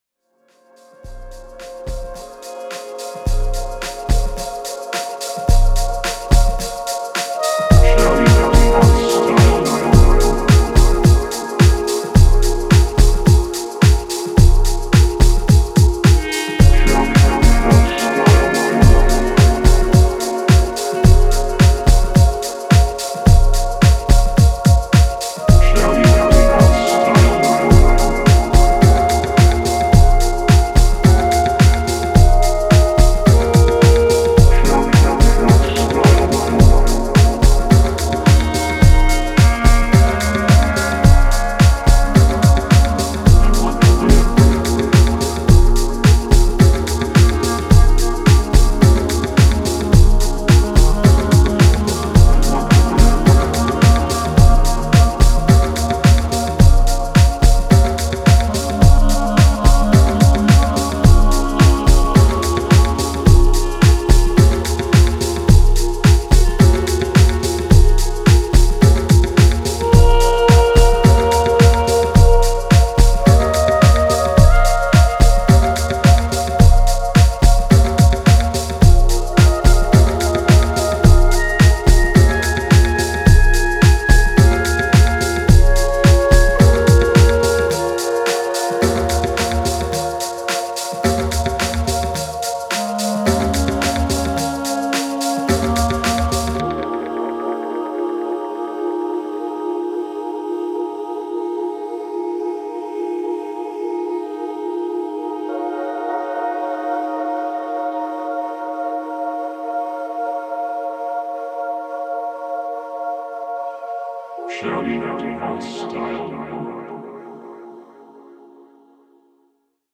スピリチュアルなディープハウス